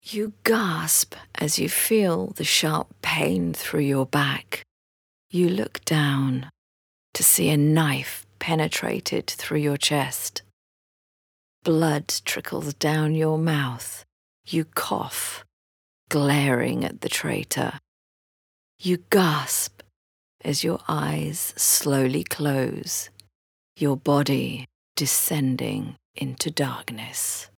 Female
Adult (30-50)
Eloquent and articulate, every single word is enunciated clearly.
Narration
Narrator Demo
All our voice actors have professional broadcast quality recording studios.